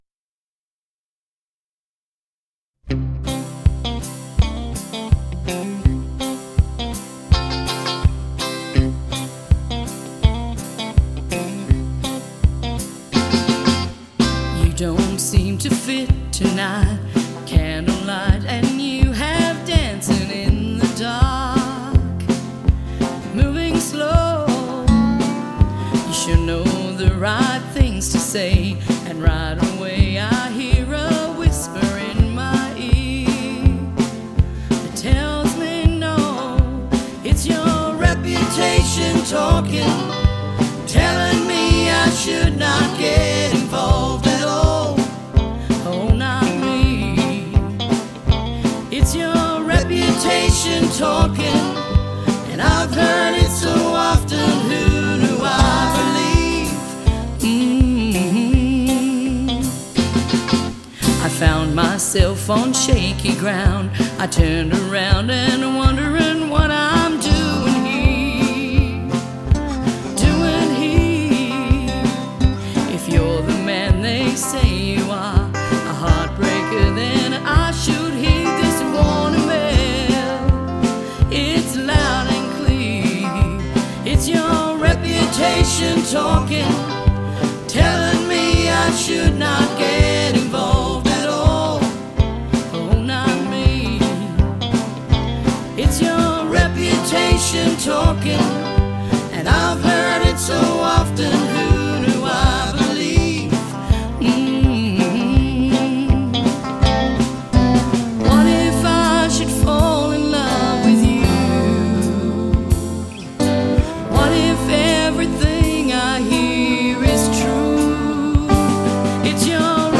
an upbeat song
country music artist.